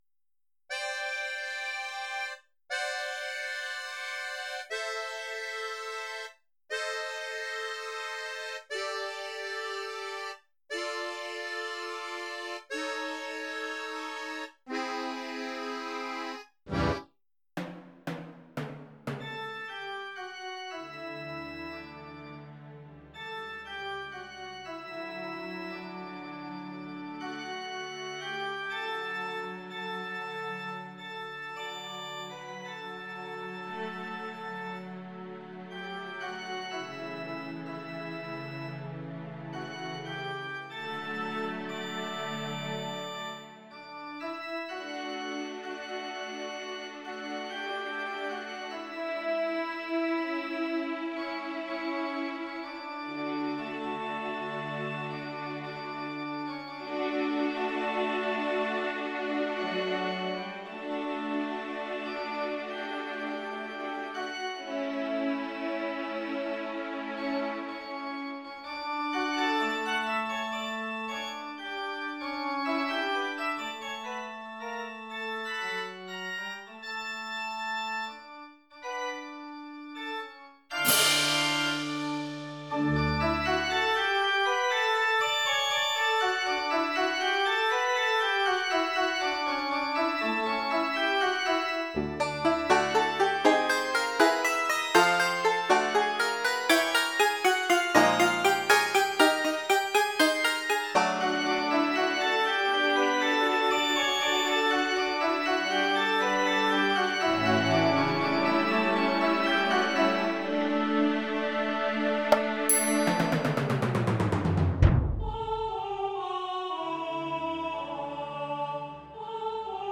Instrumentierung/Stimmen: Klavier, Klavier 2, Schlagzeug, slo strings, Akkordeon, Hexter Bass, Acc Bass, Orgel rechts, Orgel links, Banjo, ah-Chor
Damit kann das Leben dann doch lustig, leicht und beschwingt weitergehen.